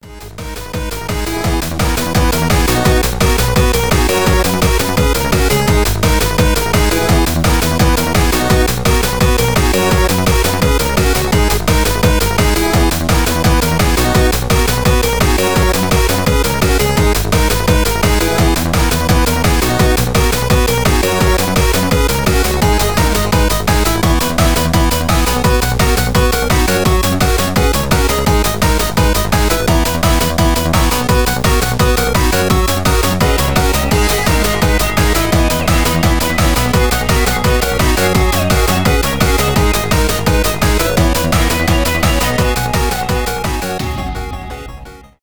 без слов , электронные